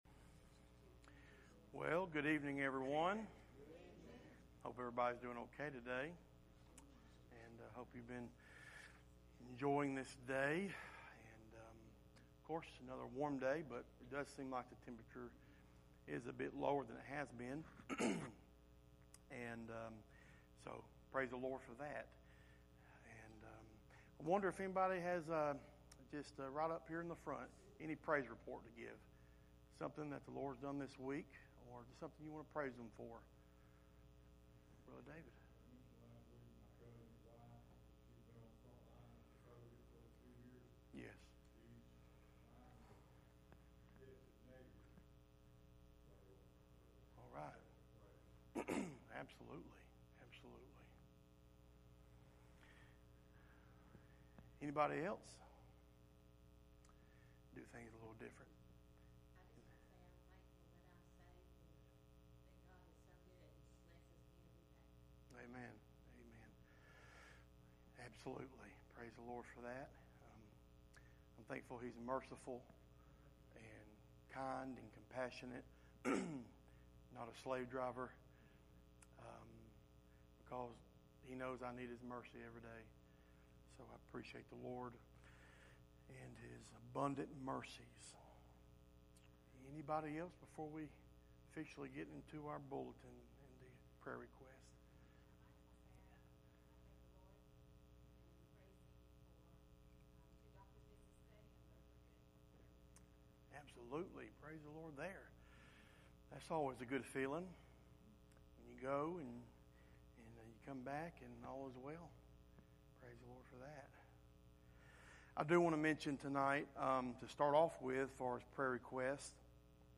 2 Corinthians 4:7 Service Type: Midweek Meeting « Rejoice